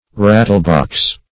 Rattlebox \Rat"tle*box`\ (r[a^]t"t'l*b[o^]ks`), n.